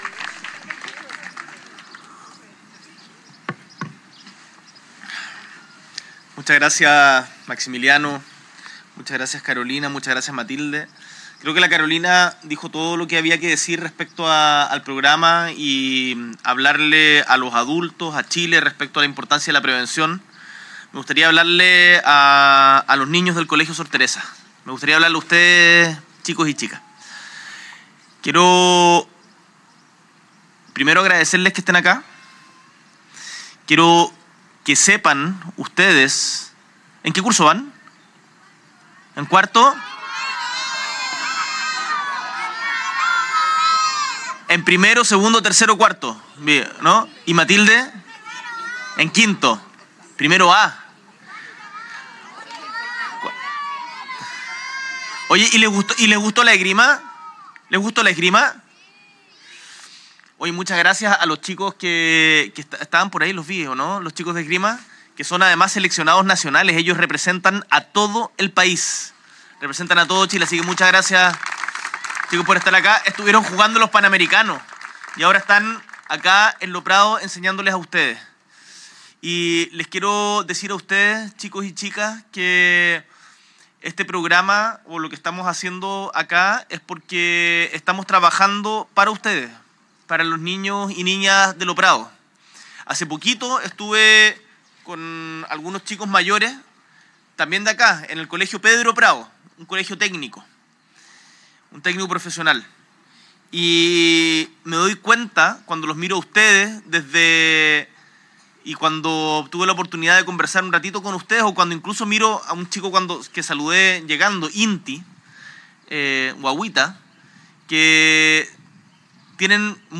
Desde el barrio Eduardo Frei de Lo Prado, el Mandatario le habló a las niñas y niños de la comuna: “Lo que importa son estos momentos, que trabajemos para mejorar la calidad de vida en la población Eduardo Frei, para que esta cancha sea ocupada por los niños y niñas, por los trabajadores, por la gente honesta y para que en todos los barrios de Chile tengan las mismas oportunidades de desarrollar sus talentos, sus intereses”.